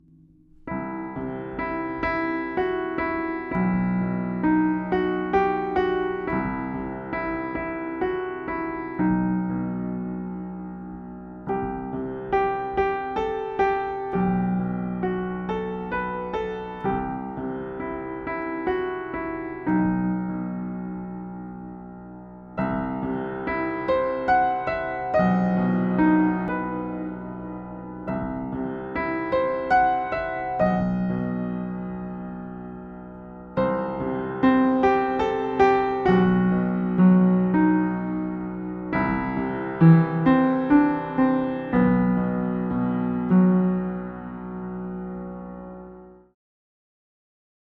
Ich habe seit Langem wieder mal was komponiert. Es ist nicht lang (bisher), nicht komplex, aber irgendwie hat es, finde ich, etwas Eigenes, und ich mag es sehr gern.